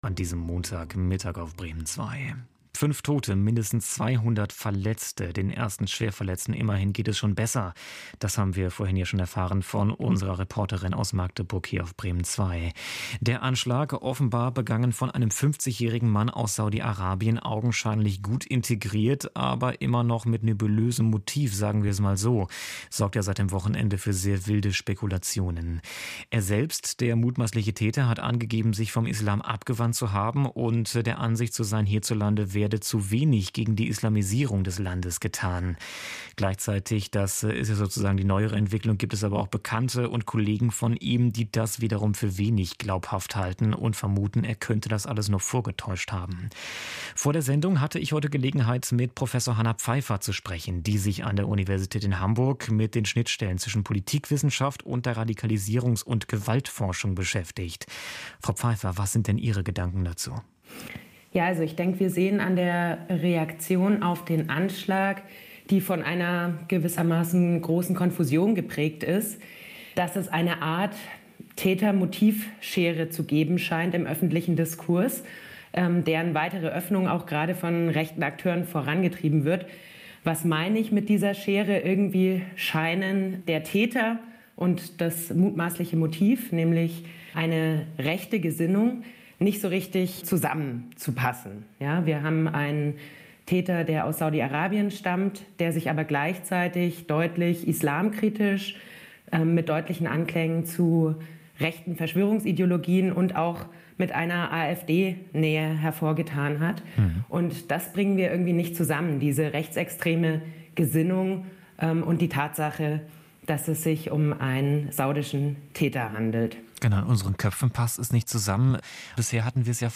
Nach dem Anschlag auf den Magdeburger Weihnachtsmarkt: Interview auf Bremen Zwei